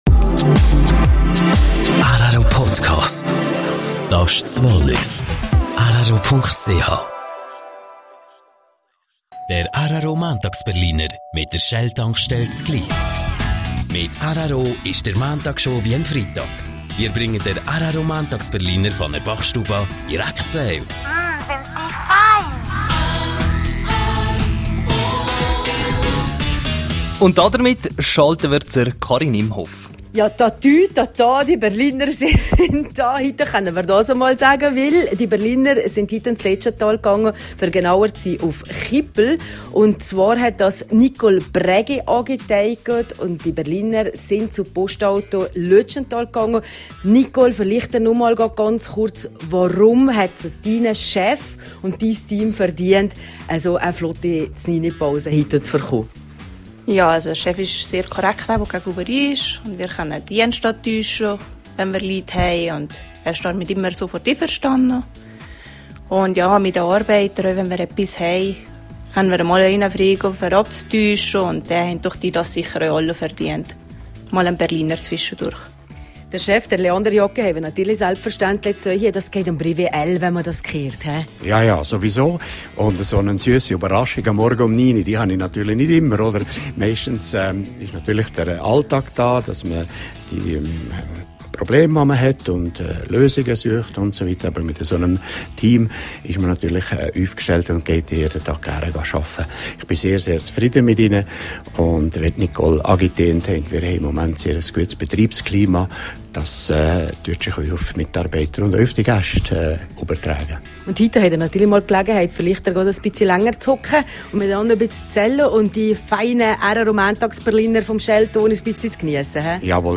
rro-Mäntagsberliner: Interview